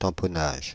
tamponnage France (Île-de-France)